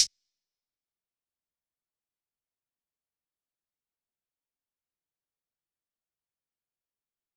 Metro Hats [PayDay].wav